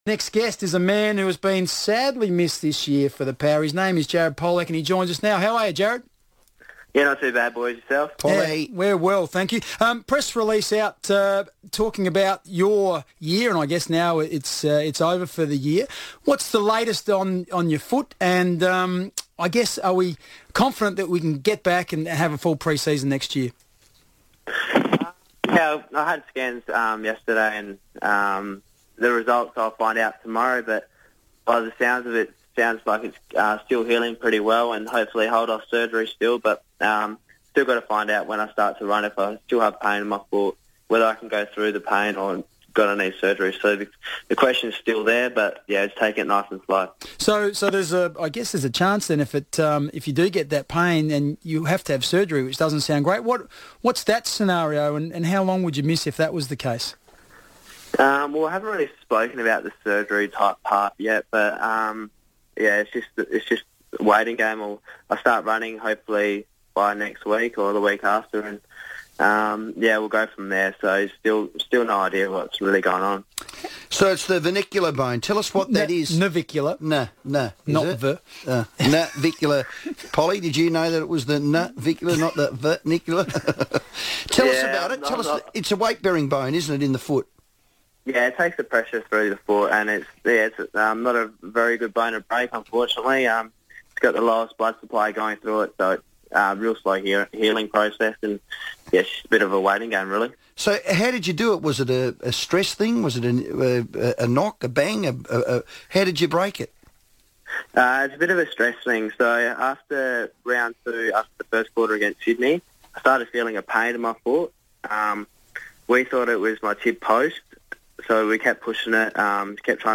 Jared Polec speaks to FIVEaa about his injury